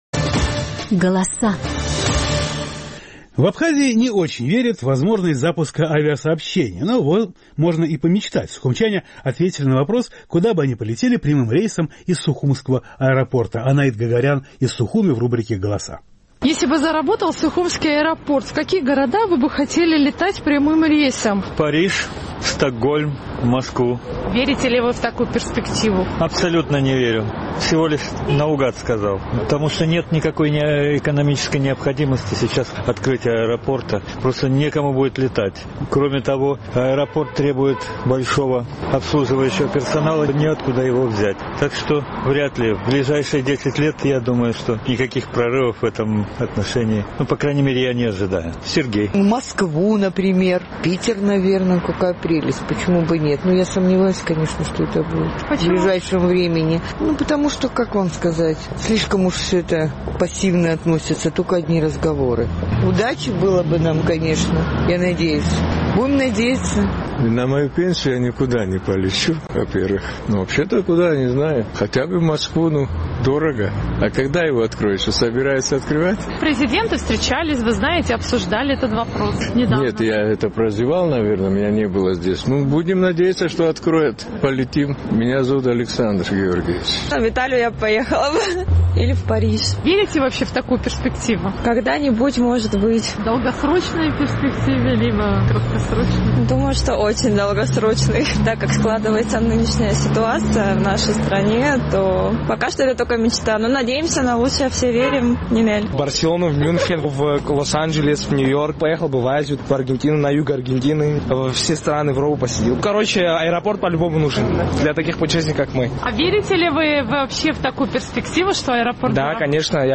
Наш сухумский корреспондент поинтересовалась у горожан, куда бы они хотели летать прямым рейсом, если бы заработал местный аэропорт.